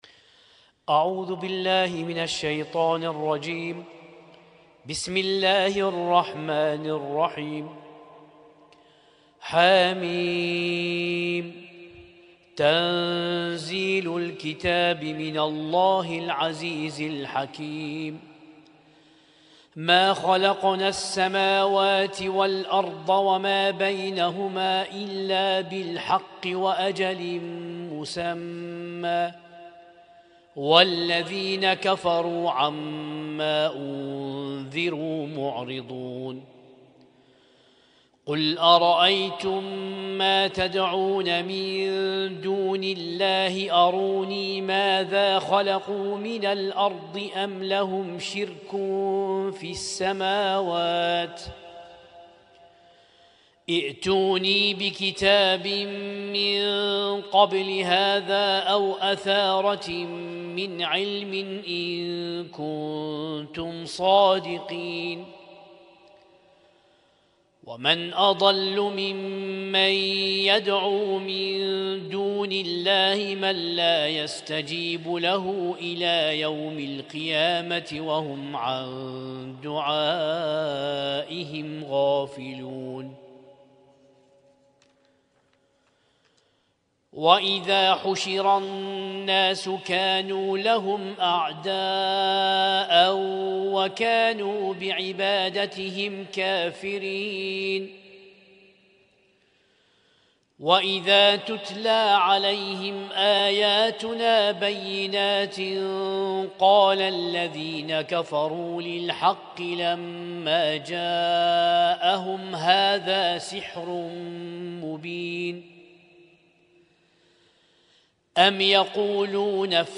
Husainyt Alnoor Rumaithiya Kuwait
اسم التصنيف: المـكتبة الصــوتيه >> القرآن الكريم >> القرآن الكريم 1447